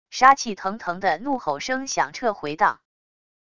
杀气腾腾的怒吼声响彻回荡wav音频